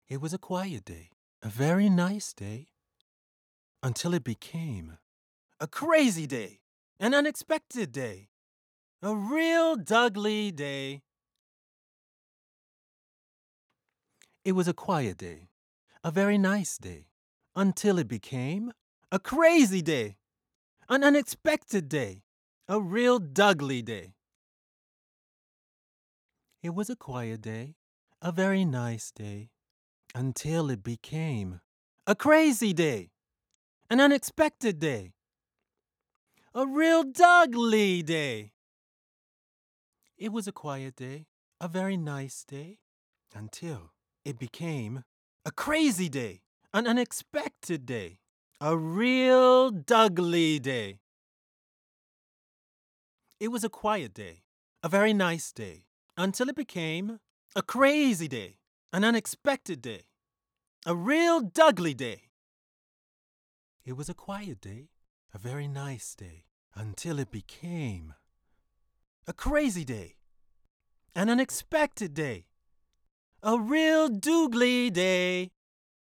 Démo Voix